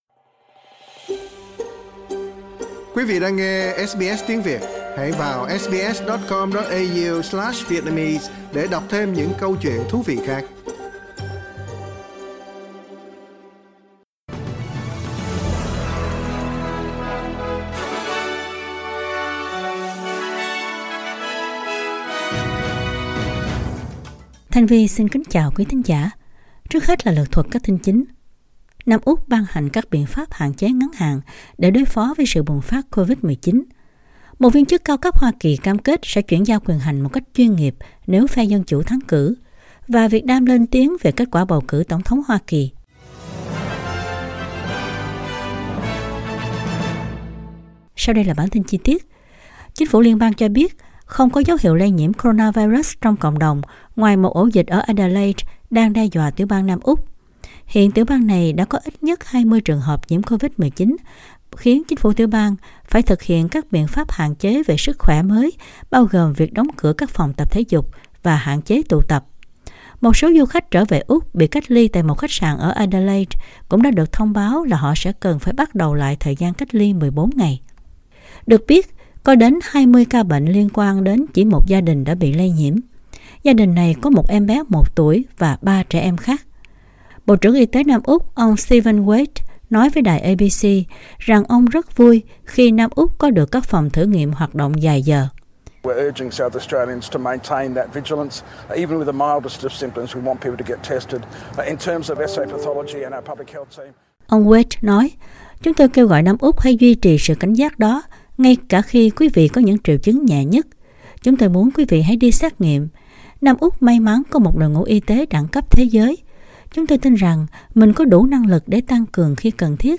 Bản tin trong ngày của SBS Radio.